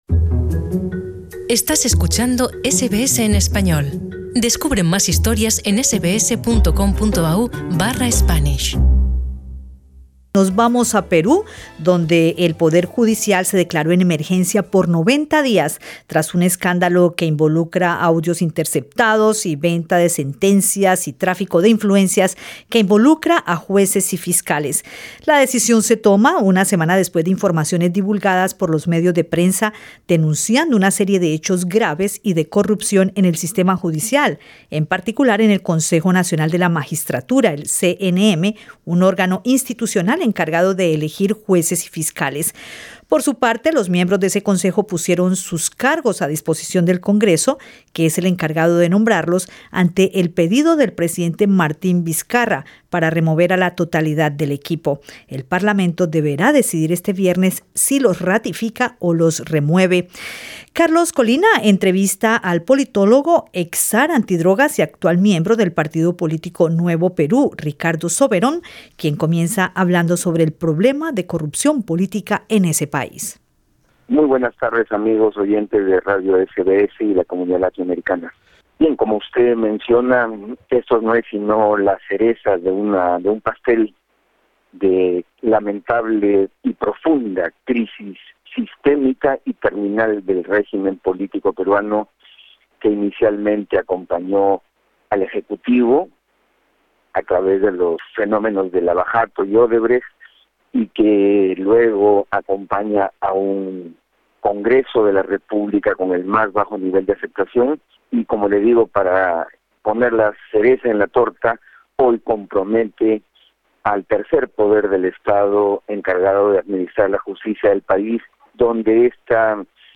Entrevista con el politólogo